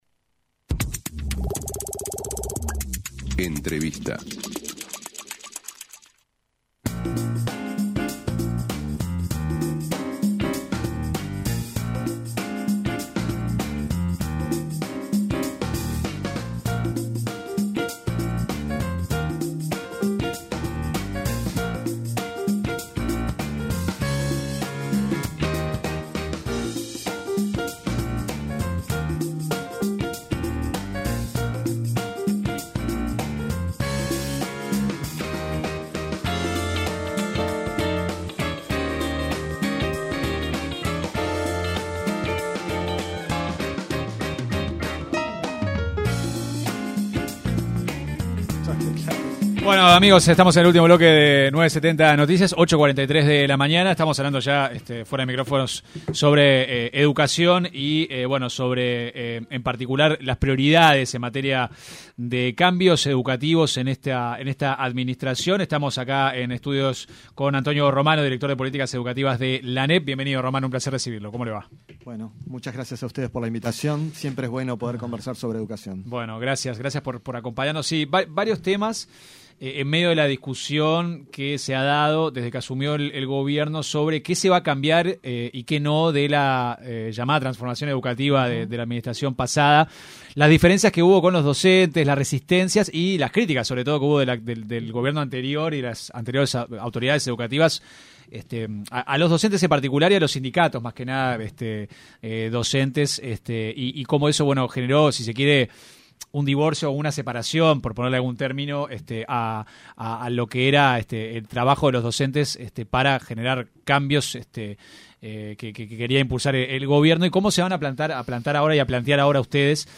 El director de Políticas Educativas de la Administración Nacional de Educación Pública (Anep), Antonio Romano, se refirió en diálogo con 970 Noticias, al tema de los exámenes y los métodos de evaluación del aprendizaje de los estudiantes.